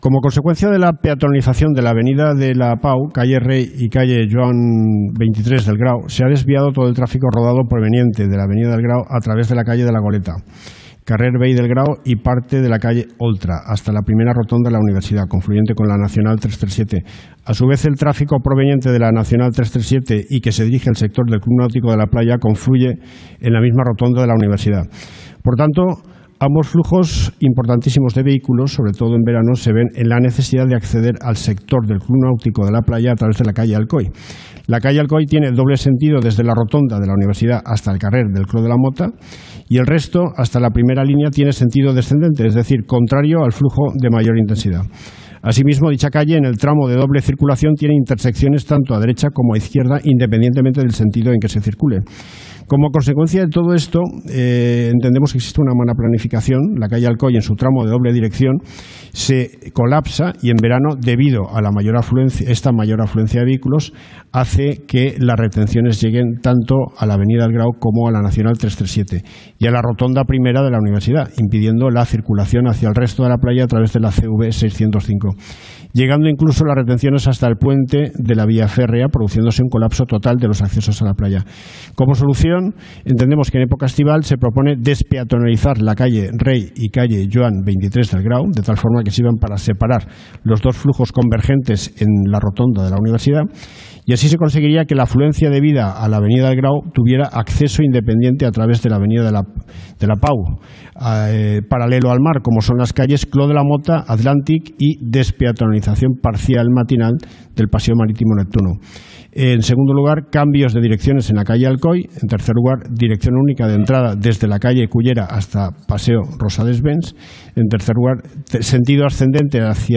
Así las cosas, otro de los que se ha sumado a la denuncia del caos de tráfico que supone acceder a la playa de Gandia ha sido el concejal de Vox, Manolo Millet que en el pleno del pasado jueves preguntó sobre el tema y aportó ideas para evitar esta cada vez más preocupante situación.